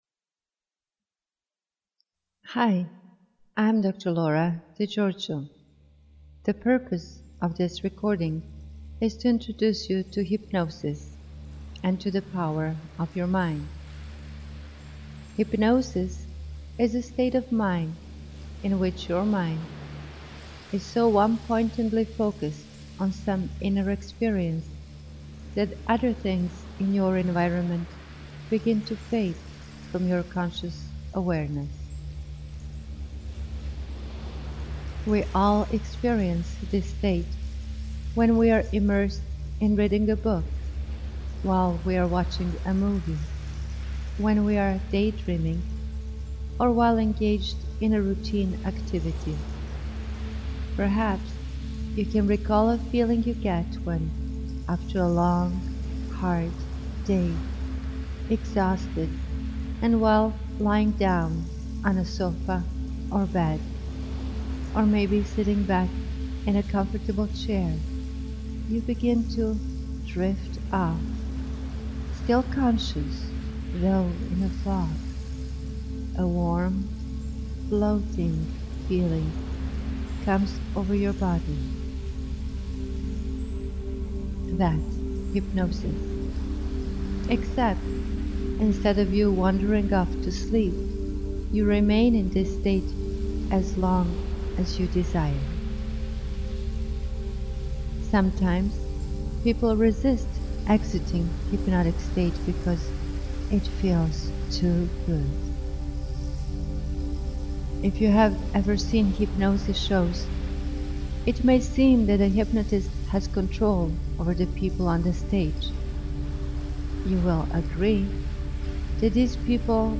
Tags: Science & Nature Hypnosis Hypnosis recording Therapy Hypnosis Audio Clips